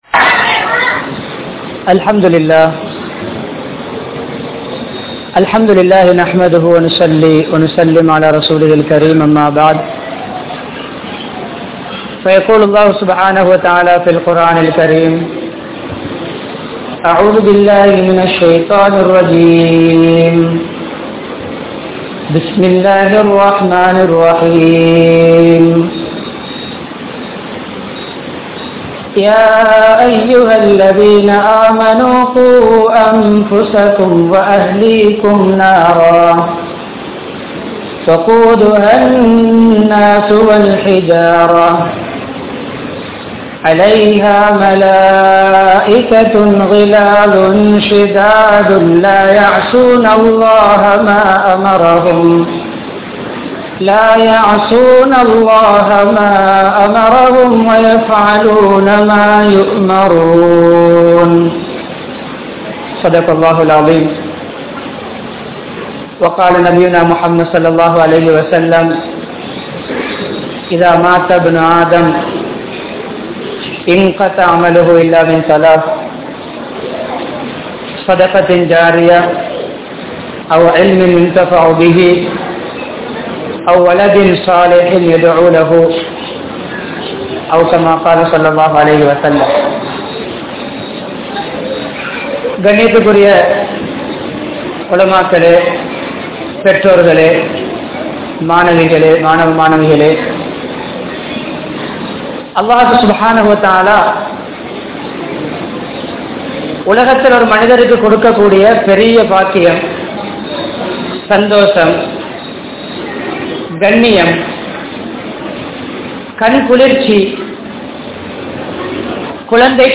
Kulanthaihalai Evvaaru Valarpathu? (குழந்தைகளை எவ்வாறு வளர்ப்பது?) | Audio Bayans | All Ceylon Muslim Youth Community | Addalaichenai
Colombo13, Kotahena, Shoe Road Jumua Masjidh